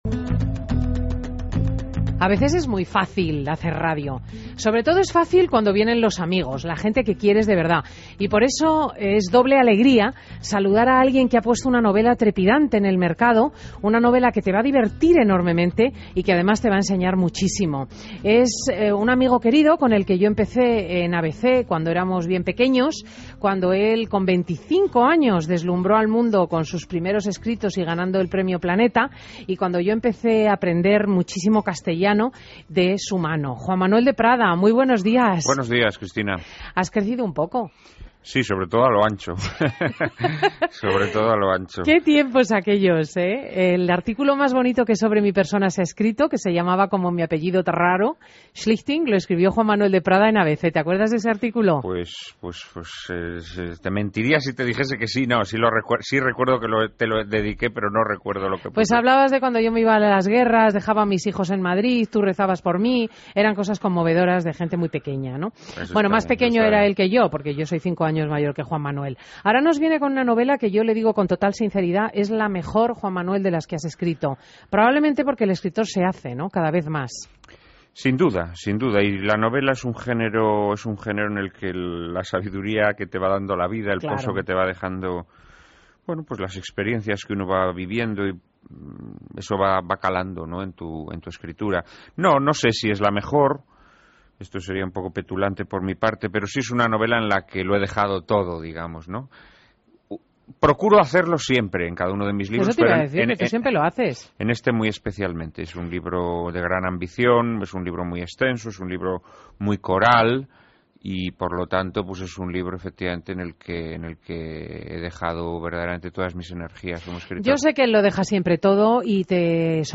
Entrevista a Juan Manuel de Prada en Fin de Semana COPE